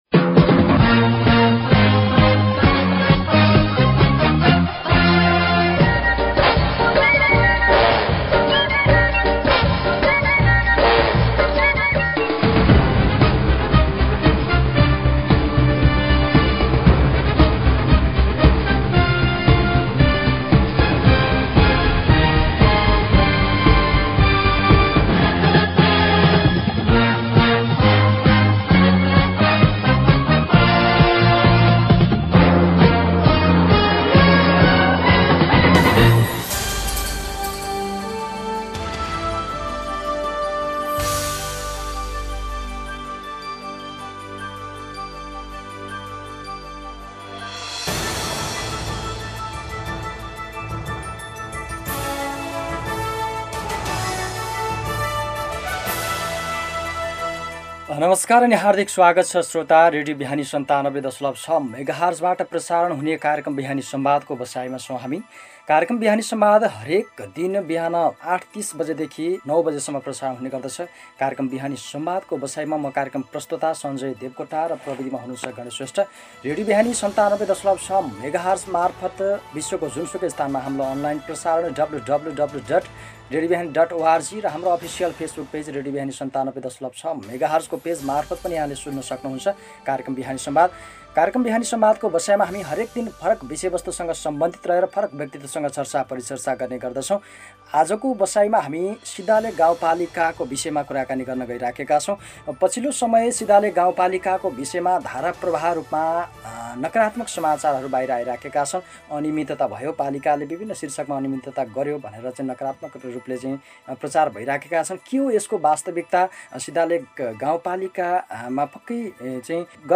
अन्तरवार्ता जस्ताको तस्तै: